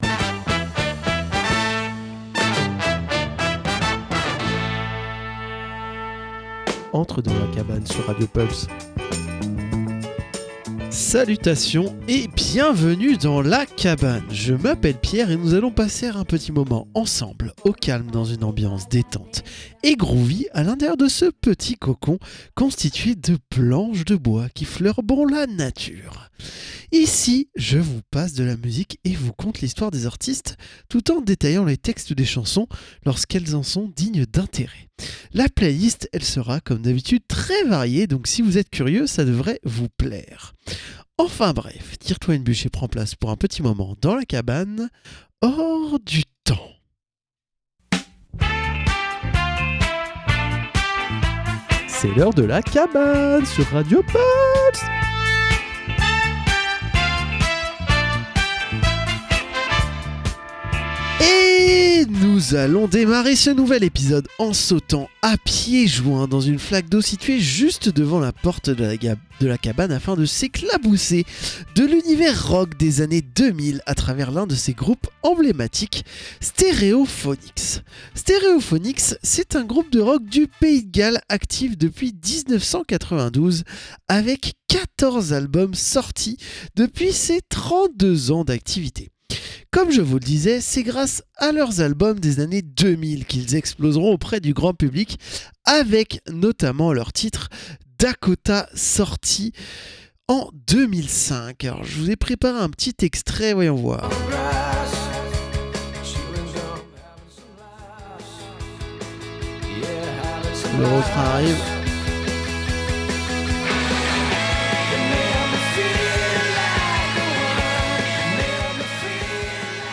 La cabane, détente dans une ambiance chill/groovy avec une playlist éclectique allant du rock à la funk en passant par la pop, le rap ou l'électro. Au programme : écoute et découverte ou redécouverte d'artistes et explication des paroles de grands classiques de la musique !